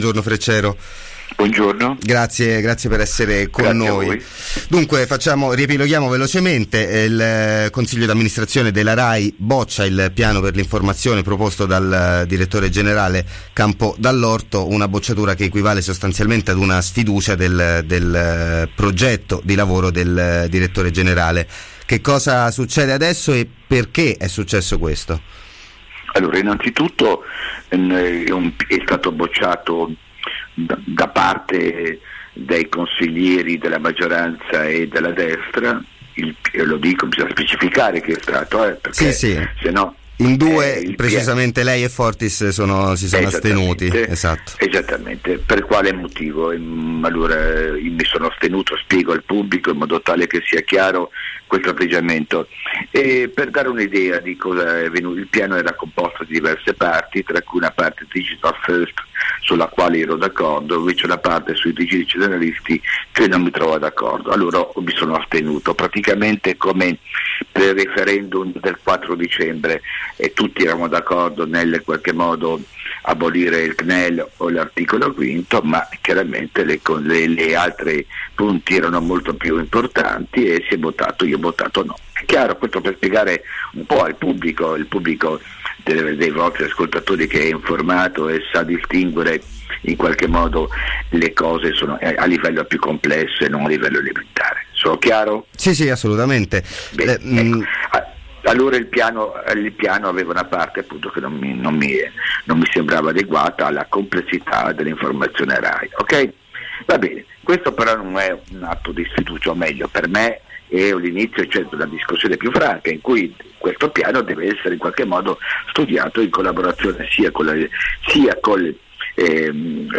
Caso Rai – Campo dall’Orto: intervista a Carlo Freccero | Radio Città Aperta